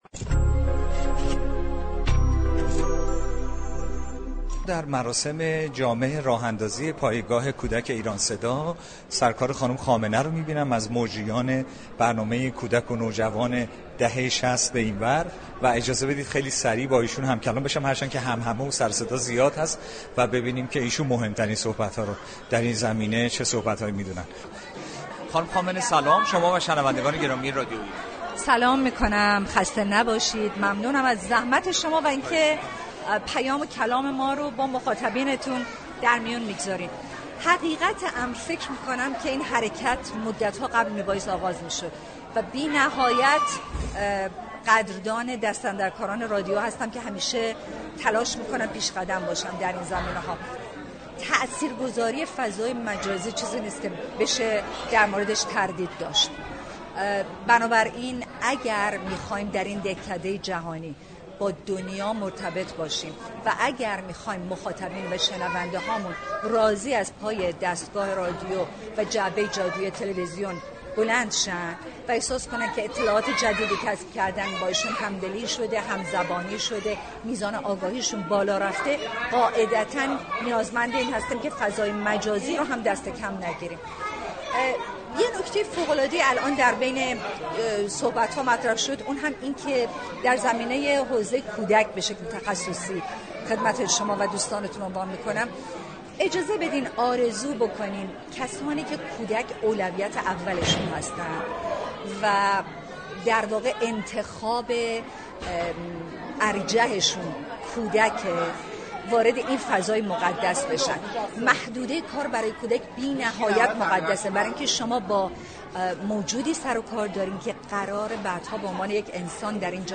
در مراسم رونمایی از پایگاه تخصصی كودك در رادیو در گفت و گو با بخش صفحه من رادیو ایران گفت